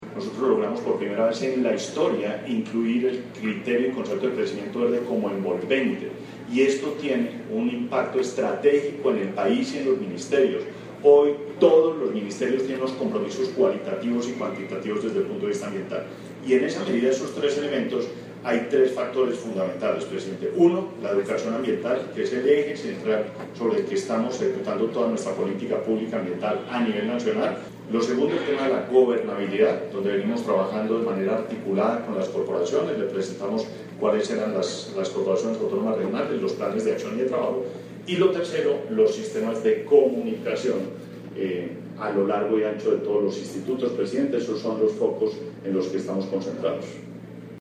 Declaraciones del Ministro de Ambiente y Desarrollo Sostenible, Gabriel Vallejo López audio